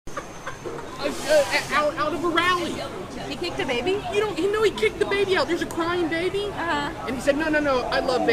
UEMalay-under-over-cop.mp3